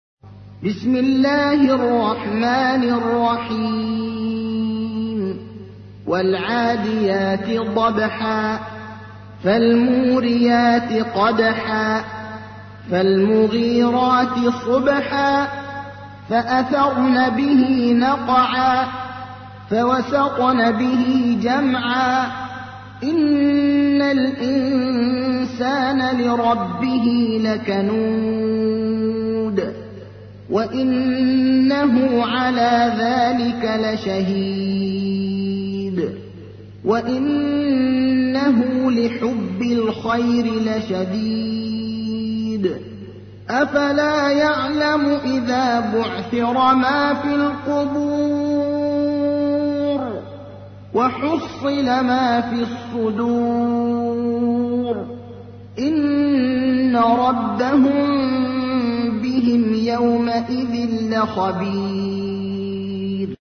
تحميل : 100. سورة العاديات / القارئ ابراهيم الأخضر / القرآن الكريم / موقع يا حسين